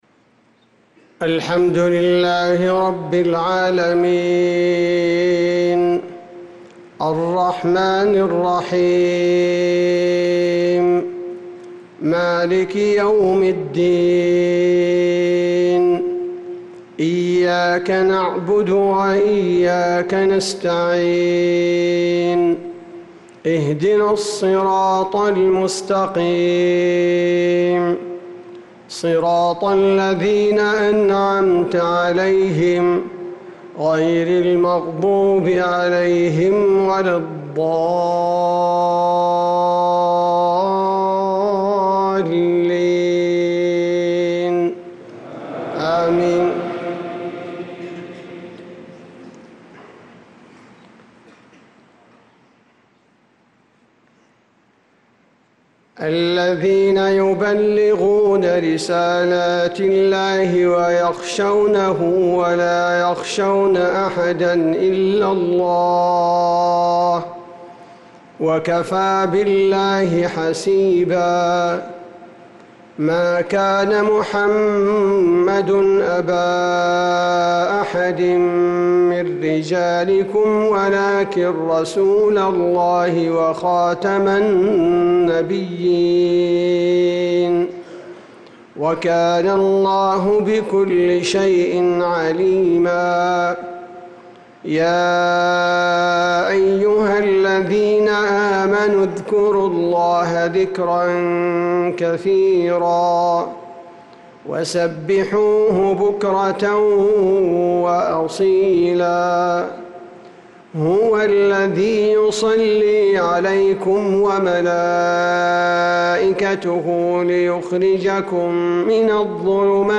صلاة المغرب للقارئ عبدالباري الثبيتي 23 ربيع الأول 1446 هـ
تِلَاوَات الْحَرَمَيْن .